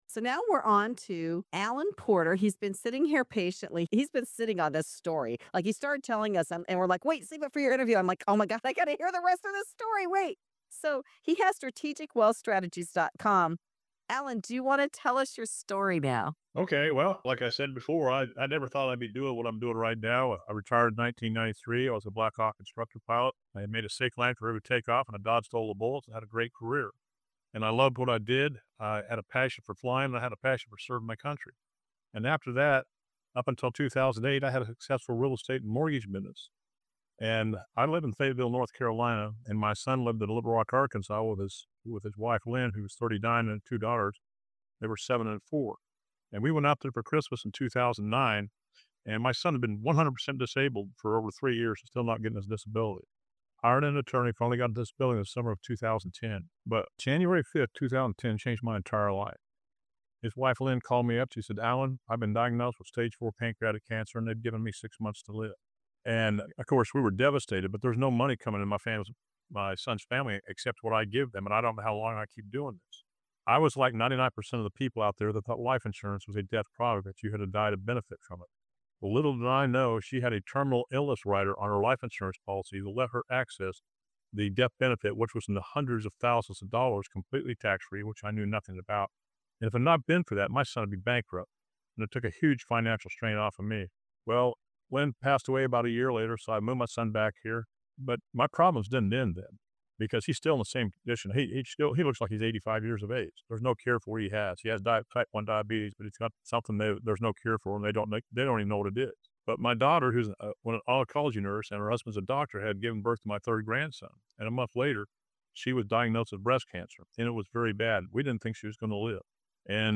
This interview is a powerful wake-up call on money, retirement, and the risks most people never see coming.